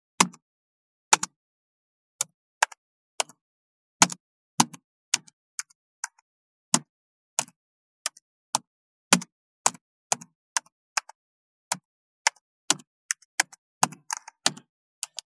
30.タイピング【無料効果音】
ASMRタイピング効果音
ASMR